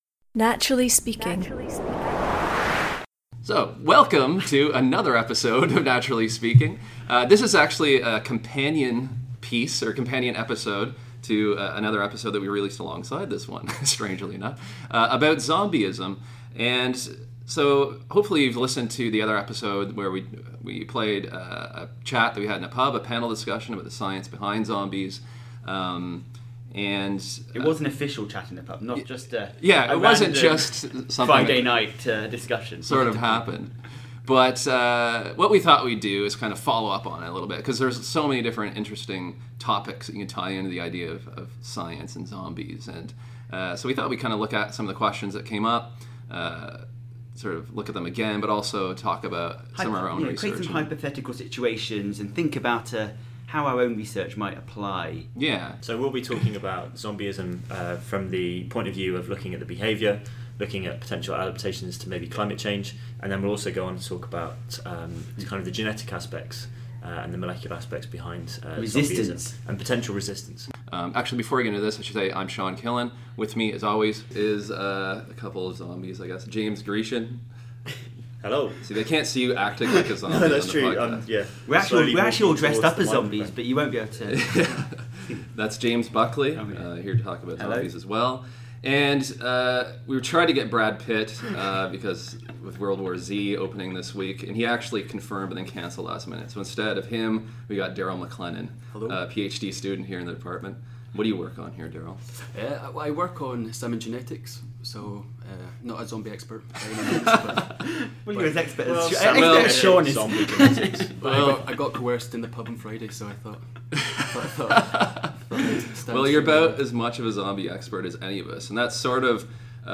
Episode 11b: Science of Zombies in Studio Chat and Follow Up